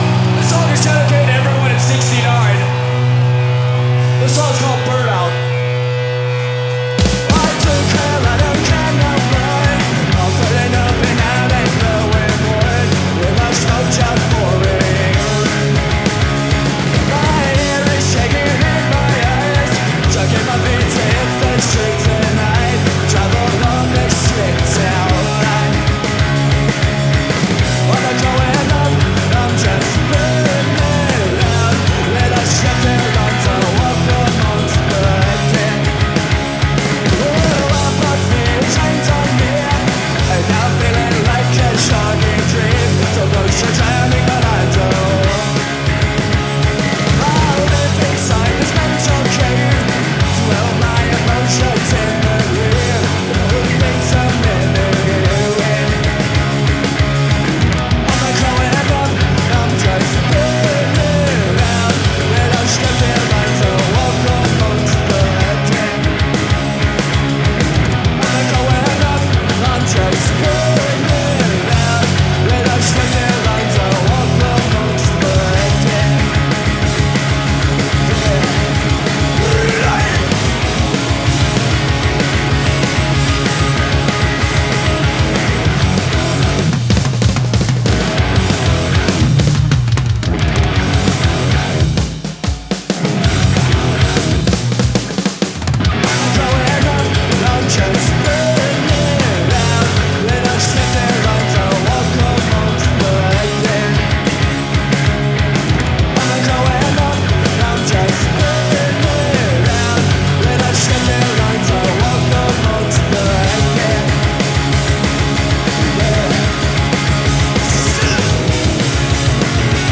Live at Woodstock 1994